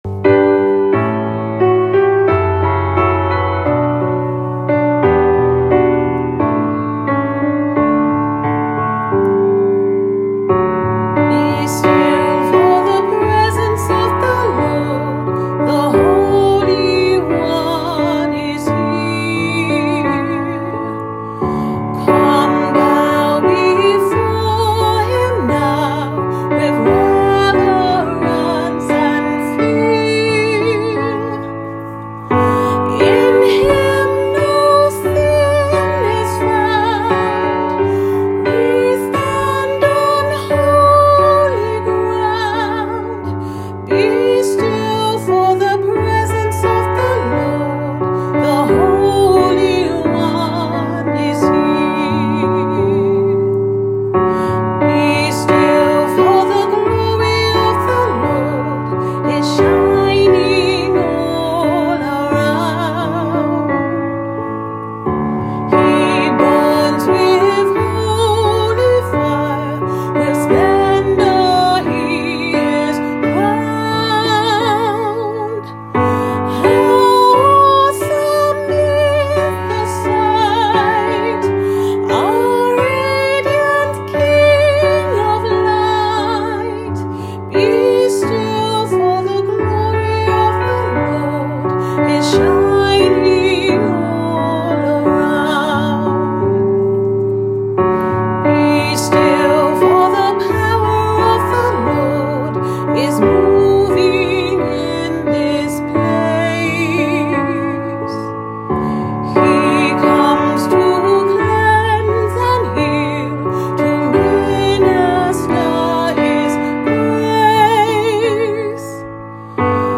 Hymn